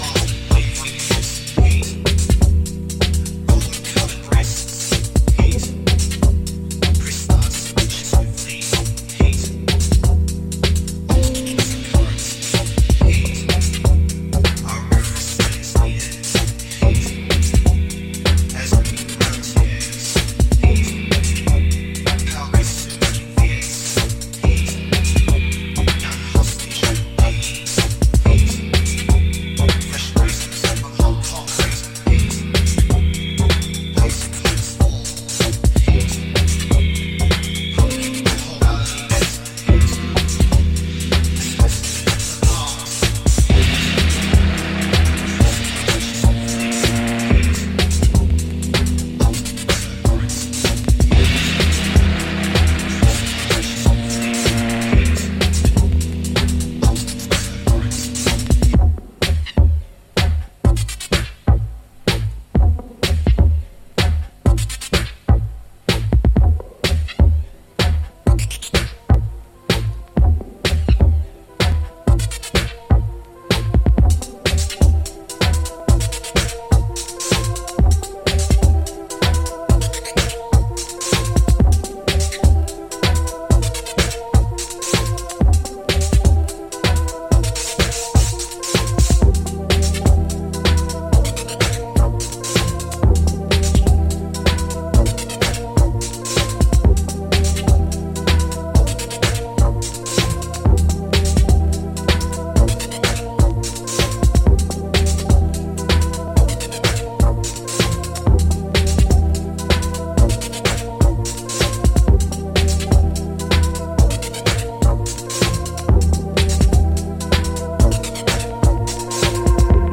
from driven trippy techno to wonky house.